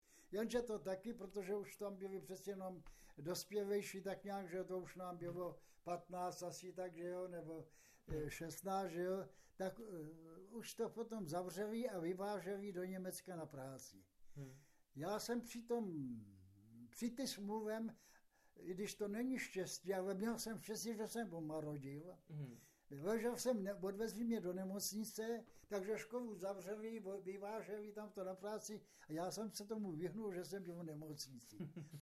z vyprávění pamětníka
v Postoloprtech